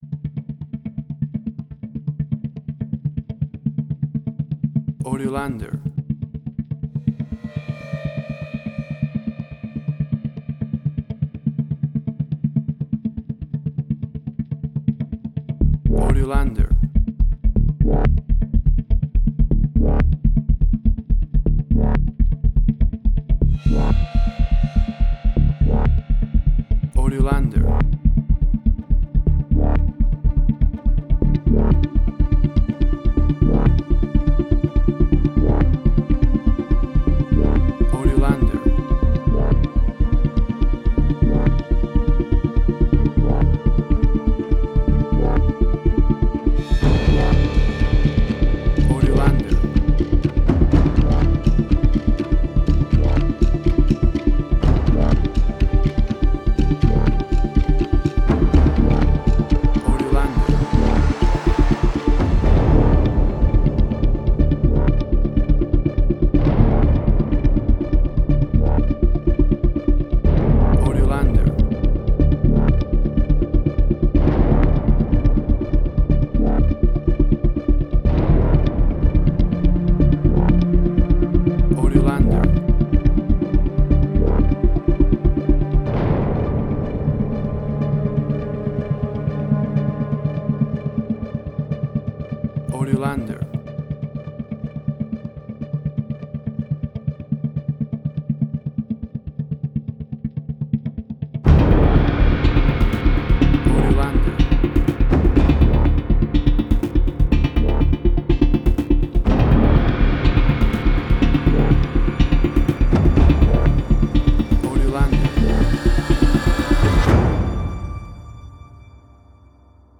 Post-Electronic.
WAV Sample Rate: 16-Bit stereo, 44.1 kHz
Tempo (BPM): 123